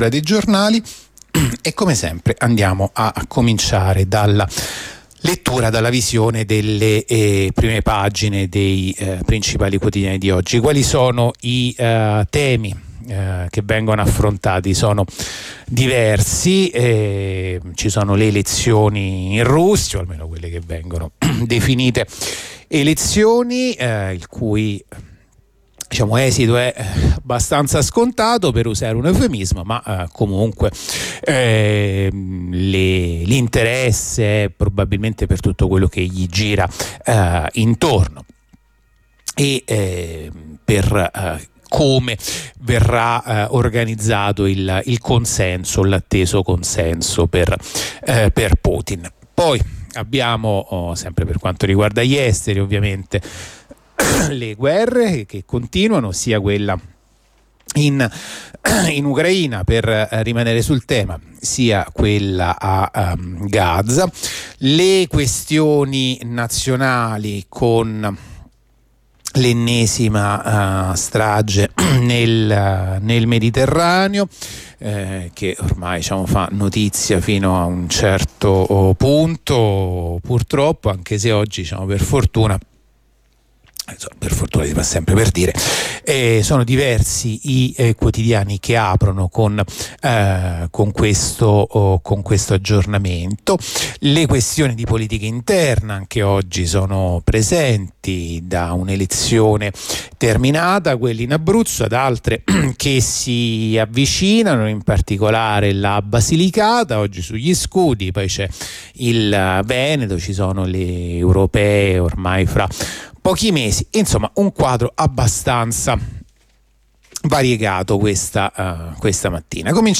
La rassegna stampa di radio onda rossa andata in onda venerdì 15 marzo 2024.